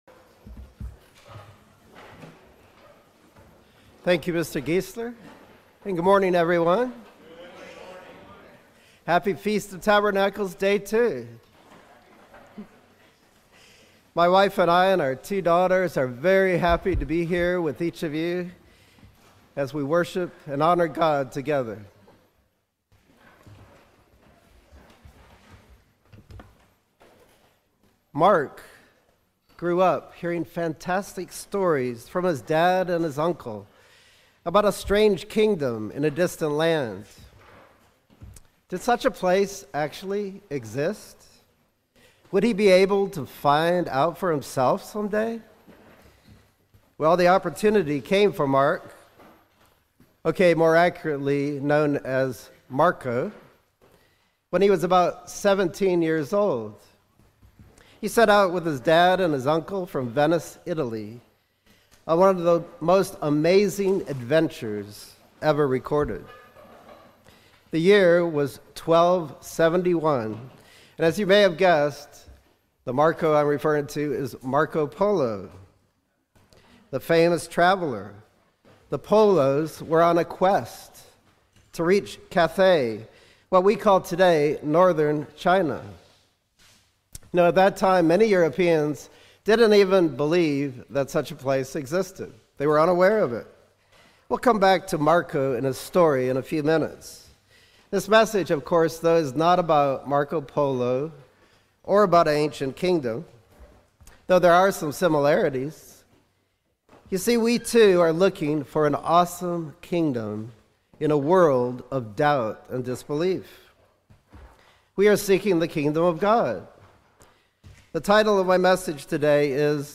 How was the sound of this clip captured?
Given in North Canton, OH Sugarcreek, OH